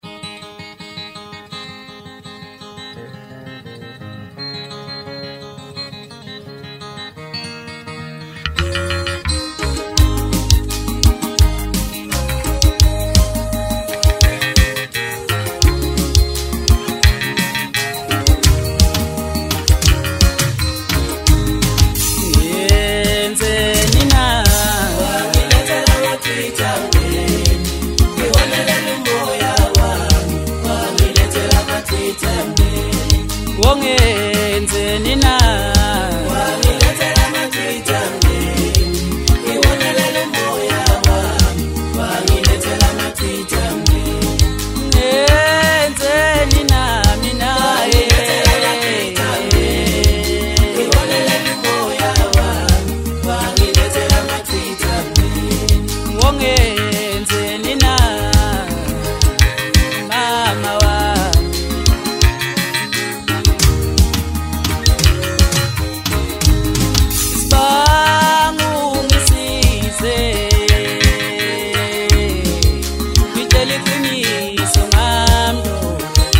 MASKANDI MUSIC
maskandi song